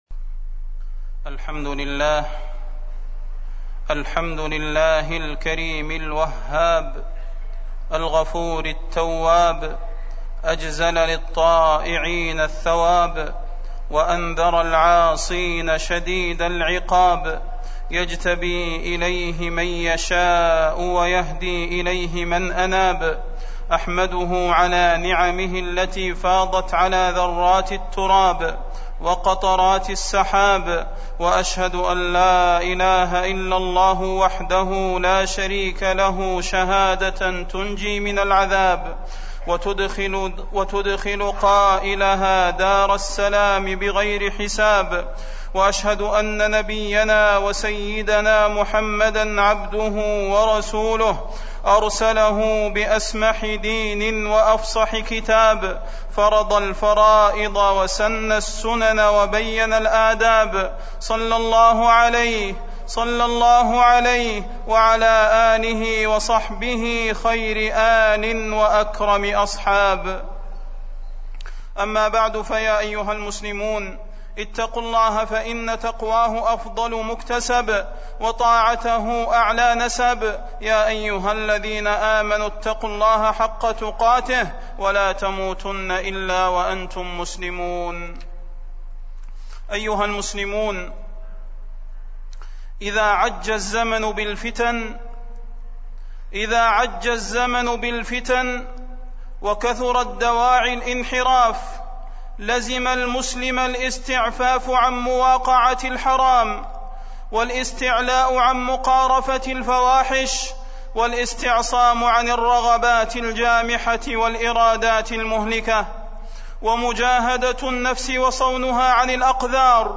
تاريخ النشر ٩ جمادى الآخرة ١٤٢٦ هـ المكان: المسجد النبوي الشيخ: فضيلة الشيخ د. صلاح بن محمد البدير فضيلة الشيخ د. صلاح بن محمد البدير الفتن